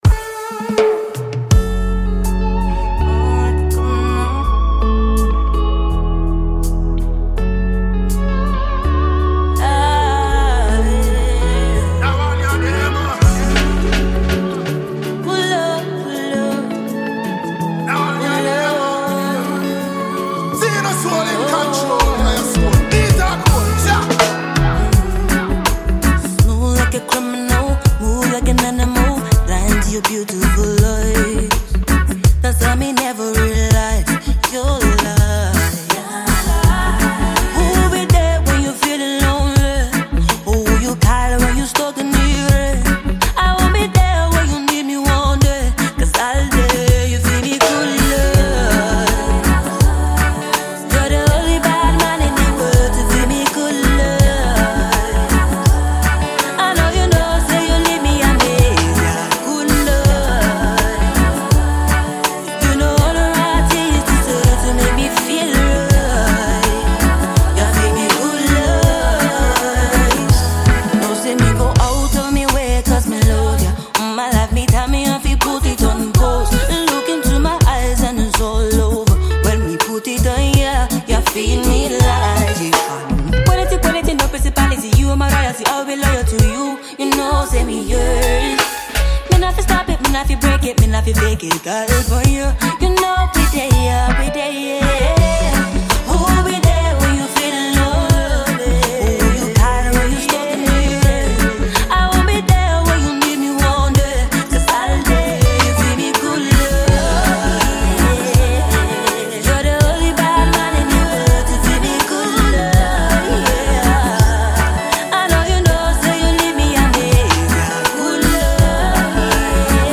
Ghanaian female vocalist and songwriter